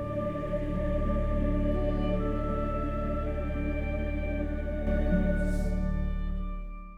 Il salto di livello si sente chiaramente (nella seconda versione) a 4,5 secondi dall'inizio.